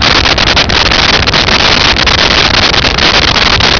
Sfx Amb Sandcrawler Loop
sfx_amb_sandcrawler_loop.wav